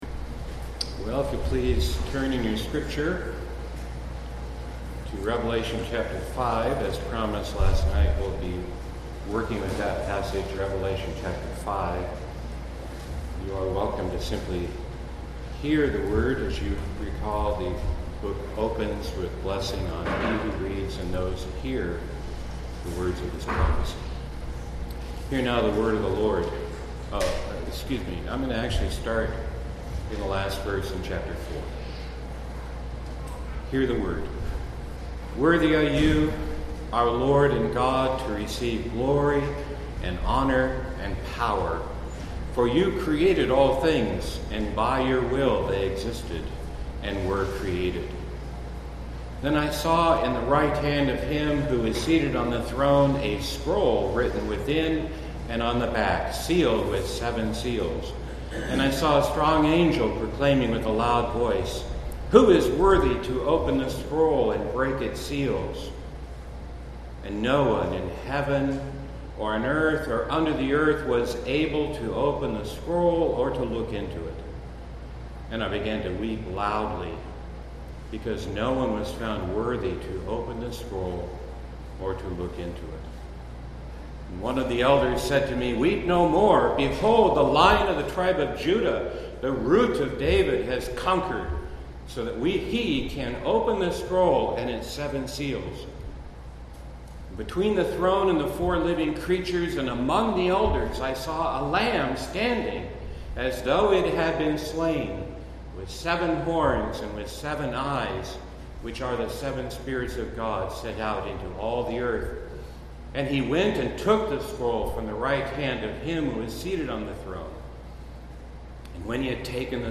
2017 Pine Mountain Church Retreat